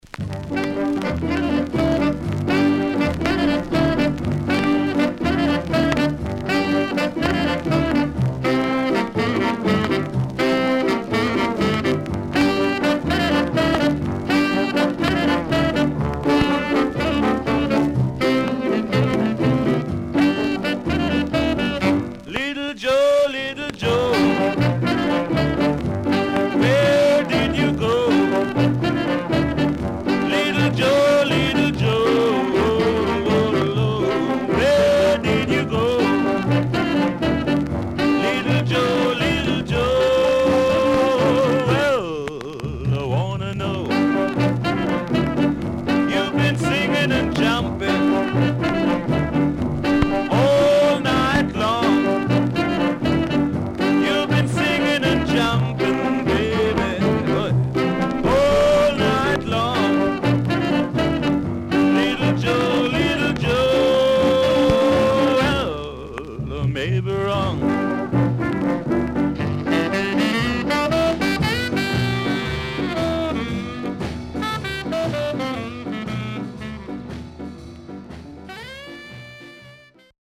Good Jamaican Shuffle
SIDE A:全体的にチリノイズがあり、所々プチパチノイズ入ります。